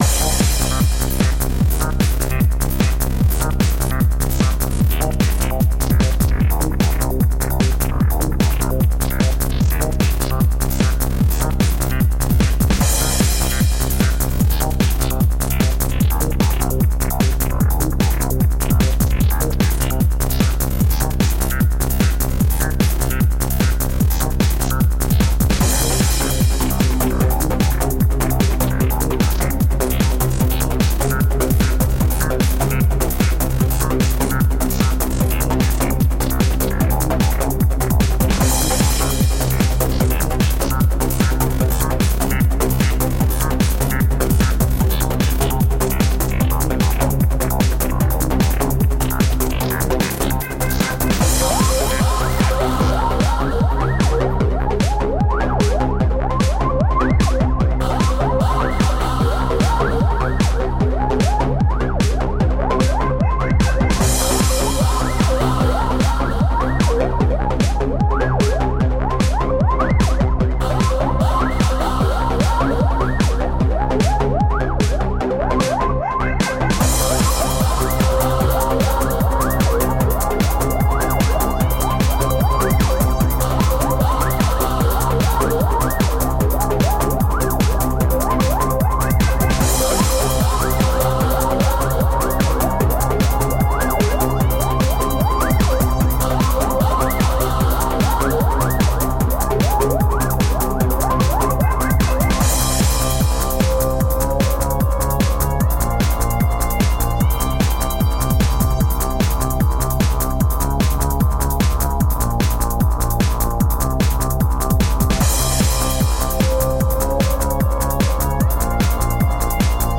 Tagged as: Electronica, Other, Hard Electronic